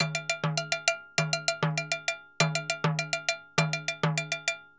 Timbaleta_Baion 100_1.wav